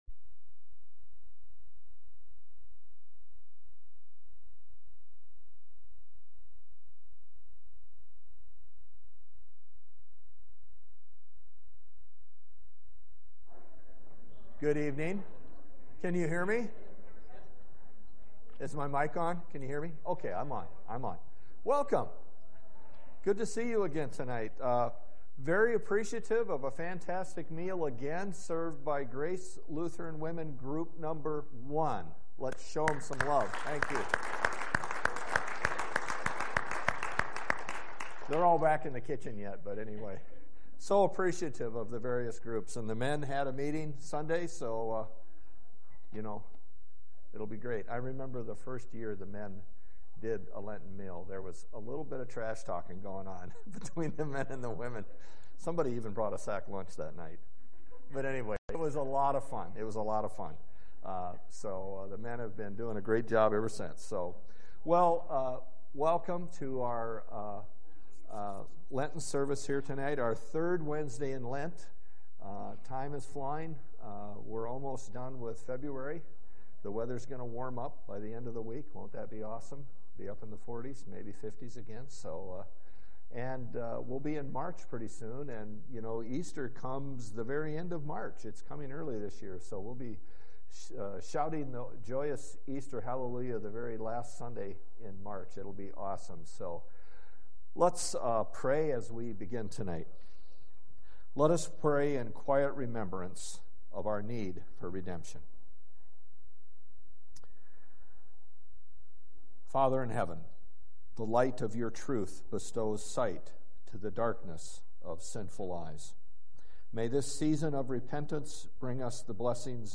Sermons | Grace Lutheran Church
Wednesday-Lenten-service-2-28-24.mp3